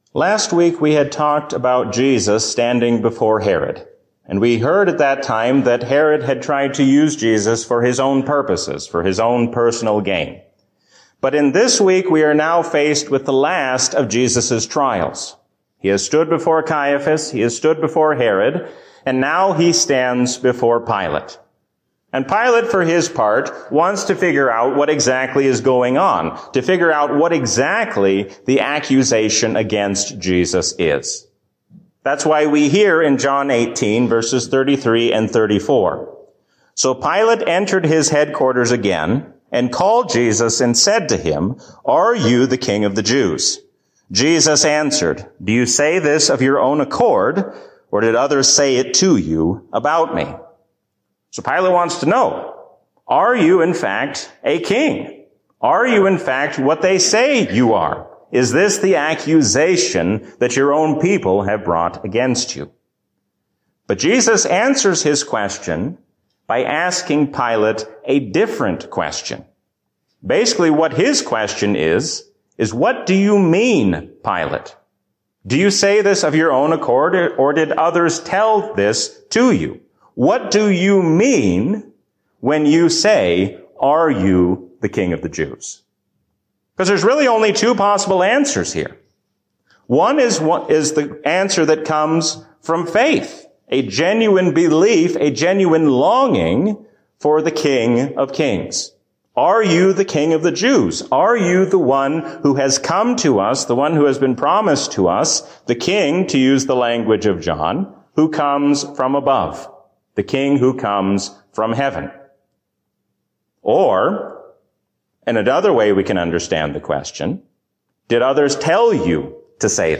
A sermon from the season "Lent 2021." God gives us hope in His Son even when the future seems uncertain.